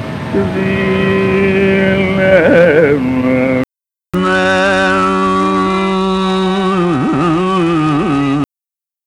There are two very short raga snippets - among the ones discussed above.
Note that I have made the snippets deliberately short, starting from pa and moving downwards.
You guys got it backwards i.e. 1 is mukhari, and 2 is huseni.